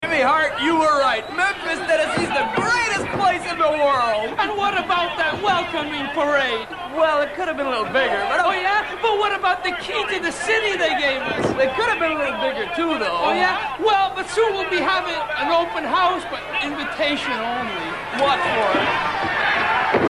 You might think of them as Hacksaw Jim Duggan, but in tag team form and with a funny accent!